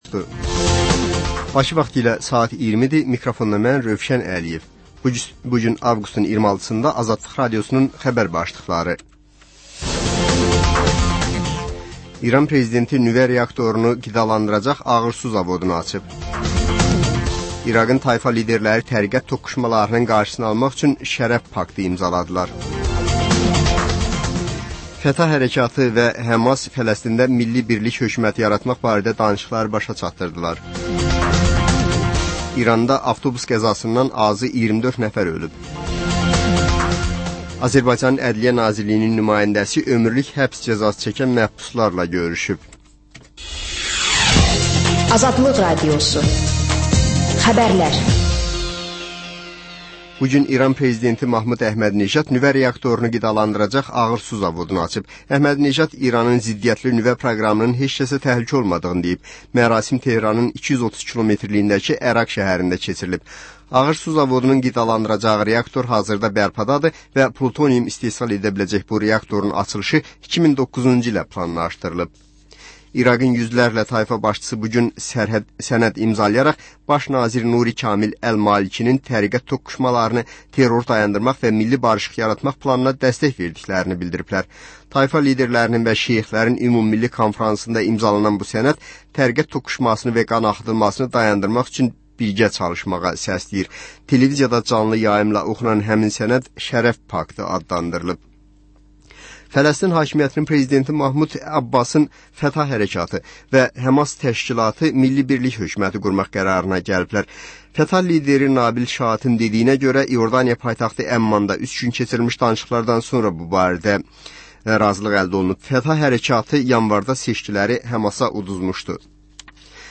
Xəbərlər, reportajlar, müsahibələr. Və: Qafqaz Qovşağı: Azərbaycan, Gürcüstan və Ermənistandan reportajlar.